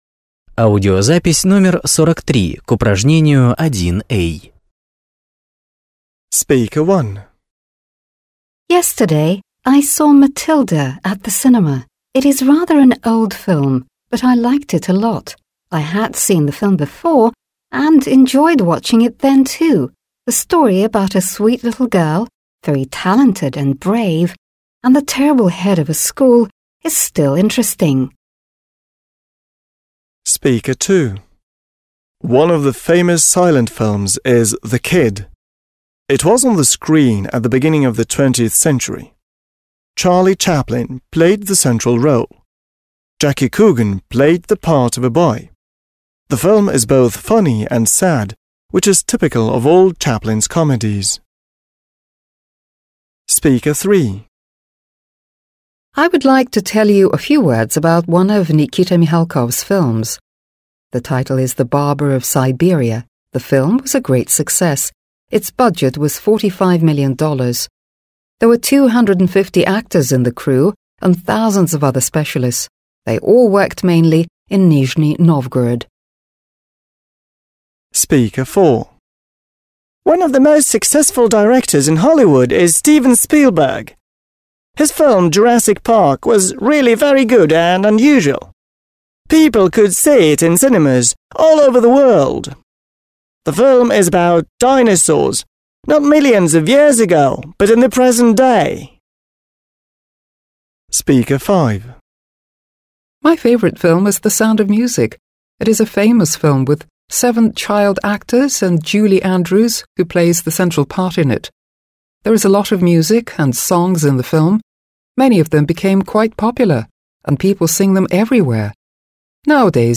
1. A. Listen to ﬁve people (1-5) speaking about different ﬁlms, (43), and match what they say with the statements (a-f).